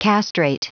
Prononciation du mot : castrate